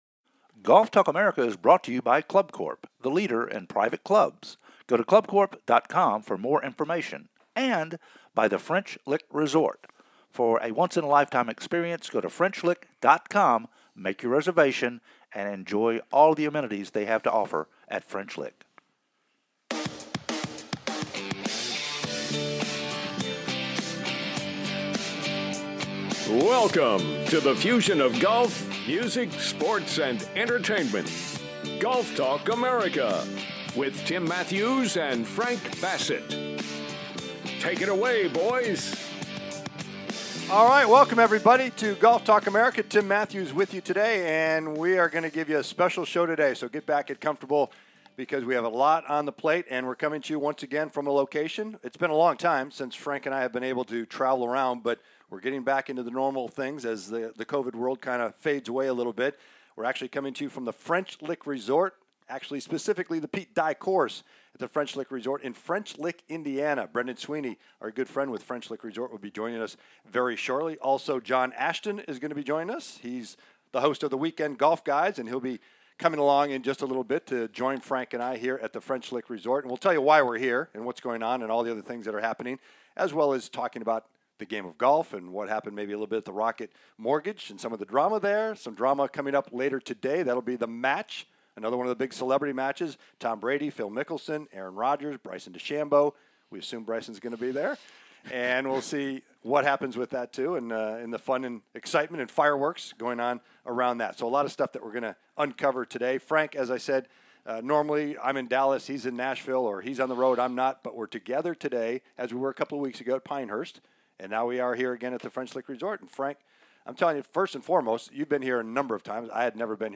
FROM THE DONALD ROSS CLASSIC "LIVE" AT THE FRENCH LICK RESORT
We are "LIVE" from The Symetra Tour's Donald Ross Classic "HOLD ON TIGHT"